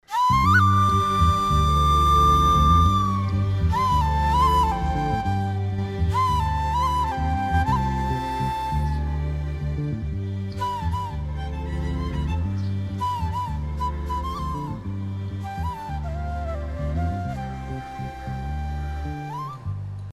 Tamil movie bgm12